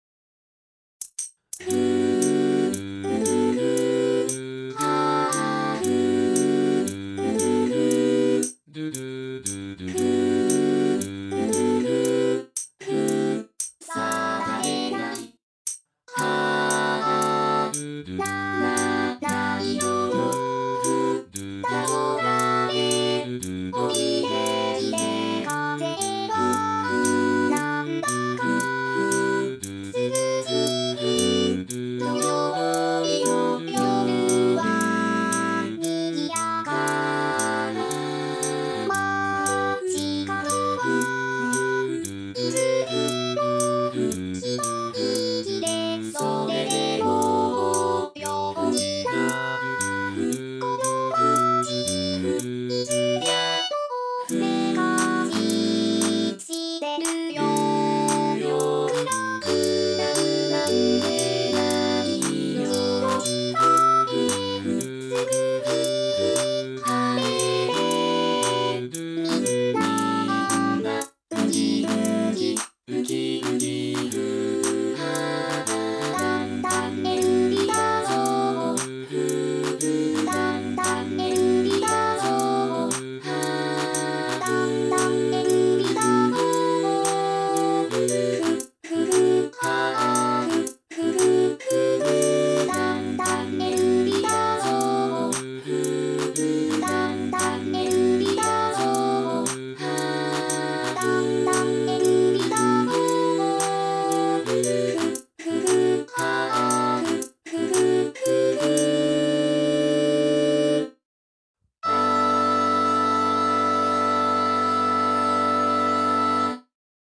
UTAU音源5枚でアカペラです。